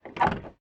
snd_dooropen.ogg